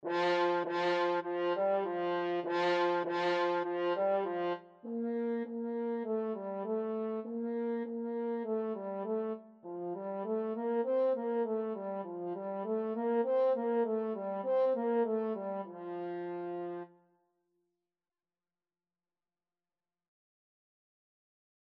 F4-C5
2/4 (View more 2/4 Music)
French Horn  (View more Beginners French Horn Music)
Classical (View more Classical French Horn Music)